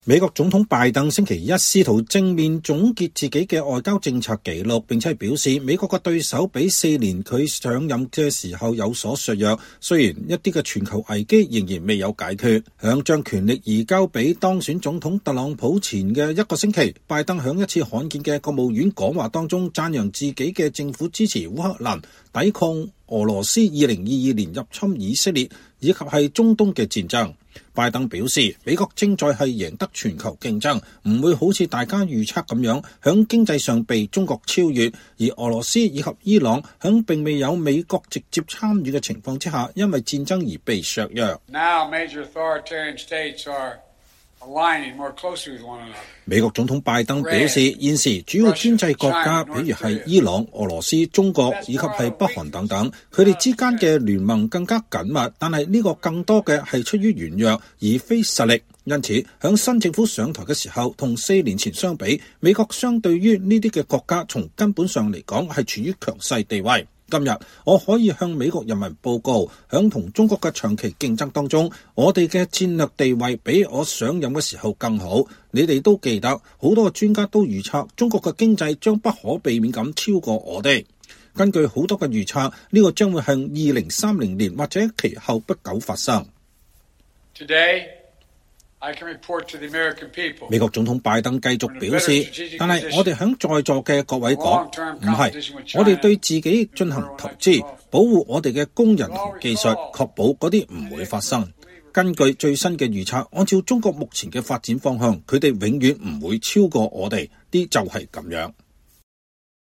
即將卸任的拜登總統在美國務院發表講話為其外交政策記錄辯護